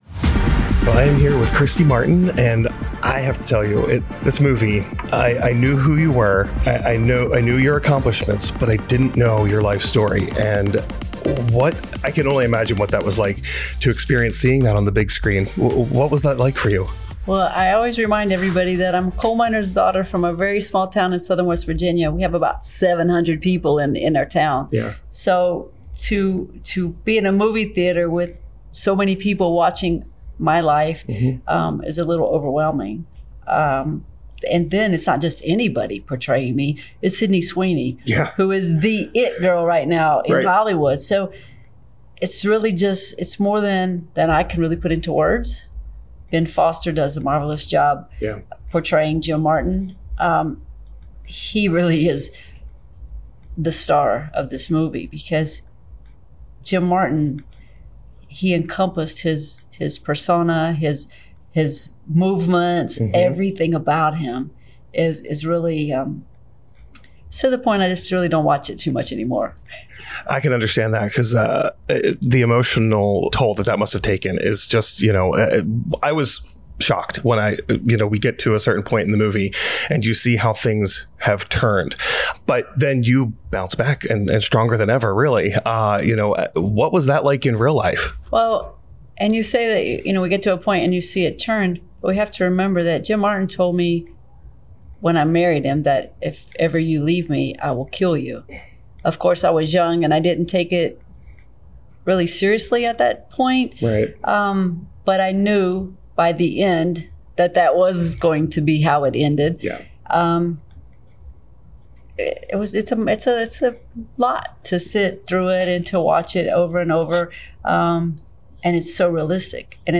I recently had the honor of speaking with Christy in person at the Caribe Royale Resort in Orlando, FL and here’s that Pride365 Conversation. It’s important to highlight Christy’s accomplishments and the struggles she endured as a member of the LGBTQ+ community.